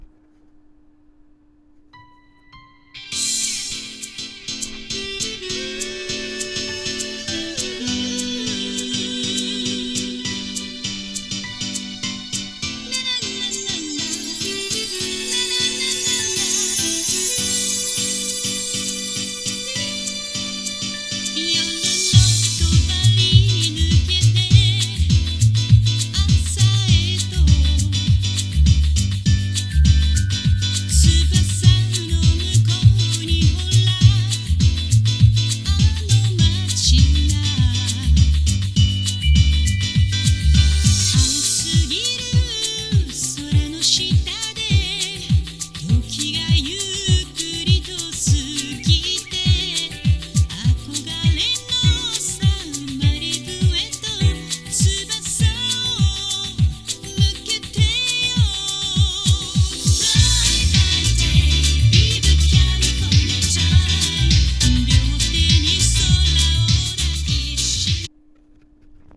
・オリンパス ステレオICレコーダー LS-20M：リニアPCM 44.1kHz/16bit 無圧縮.WAV
というか、一番近いのがApple純正イヤフォンで、左肩上がりの低音重視の音質です。